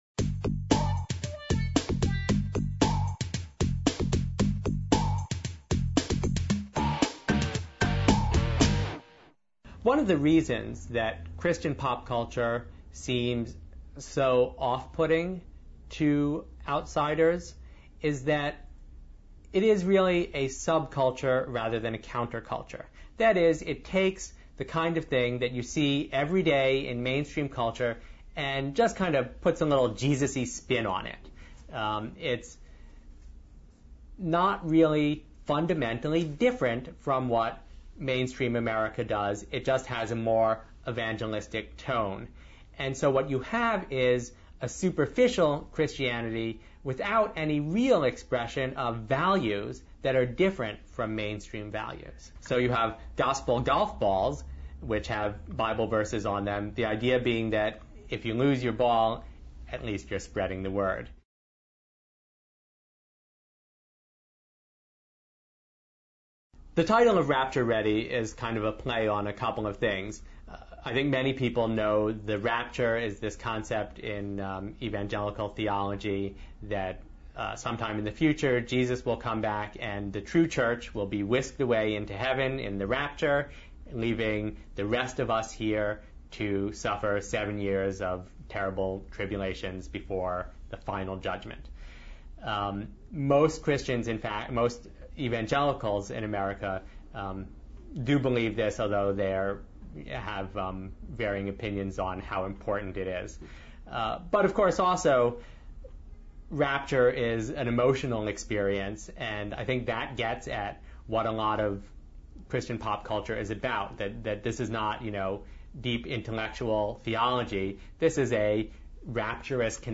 This eight-minutue interview